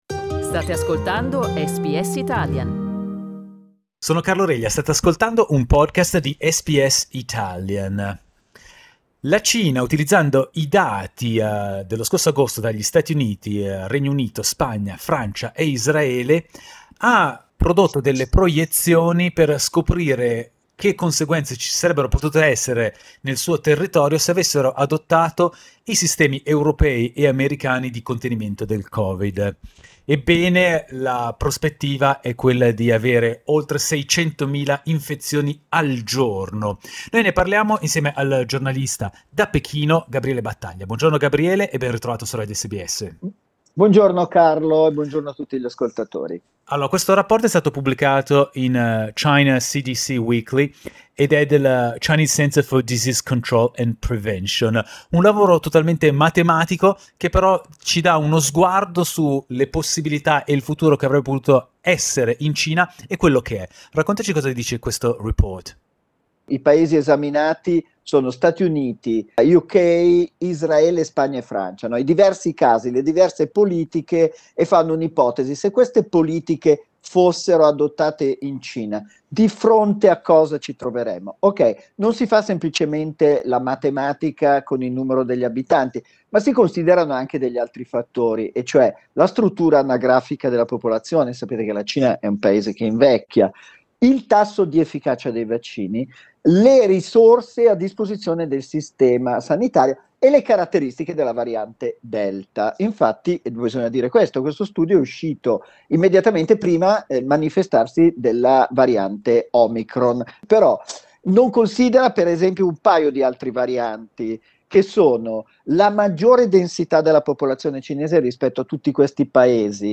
Il giornalista da Pechino